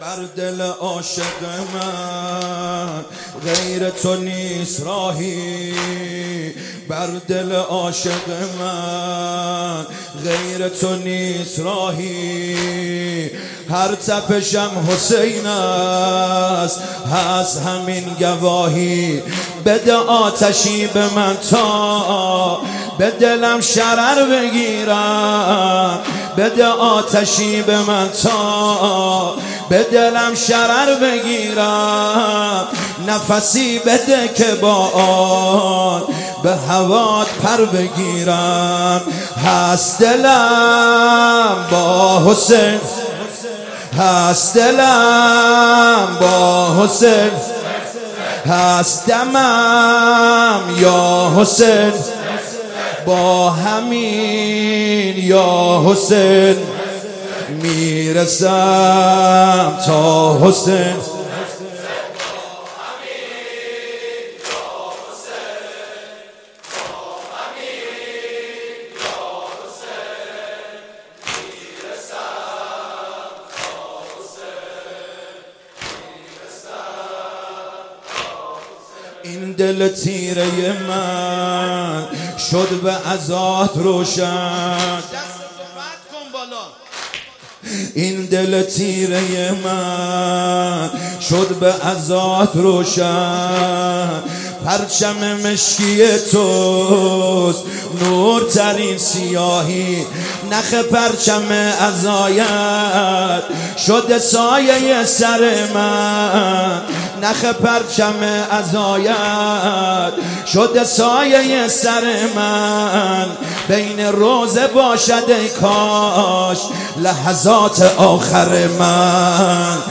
مداحی جدید
شب دوم محرم97 هیات کربلا رفسنجان